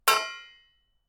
Ting sound from a metal cup
cup ding metal recording spoon stereo ting sound effect free sound royalty free Sound Effects